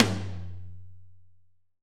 Index of /90_sSampleCDs/AKAI S6000 CD-ROM - Volume 3/Drum_Kit/AMBIENCE_KIT3
R.AMBTOMM2-S.WAV